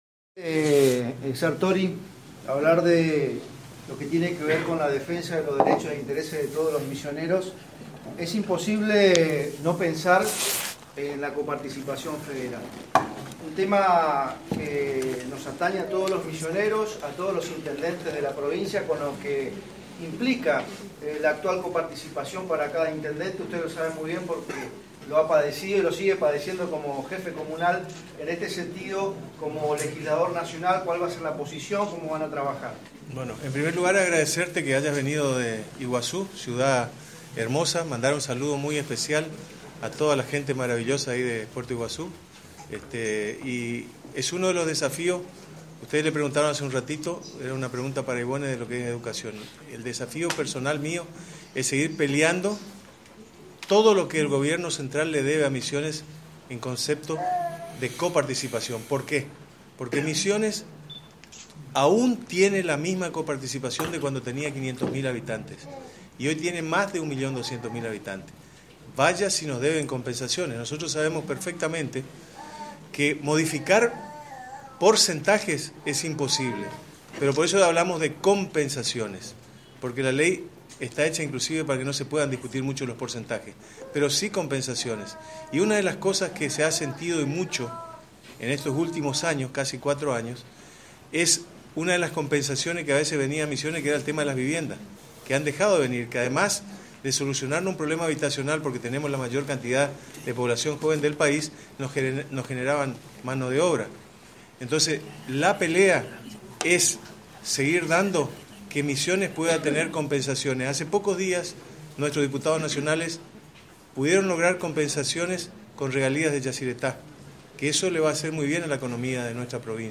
Así lo aseguró el actual intendente de Leandro N. Alem y primer candidato a Diputado Nacional por el Frente Renovador Diego Sartori, este fin de semana en la localidad de Aristóbulo del Valle en un encuentro con todos los periodistas de la Agencia de Noticias Guacurarí.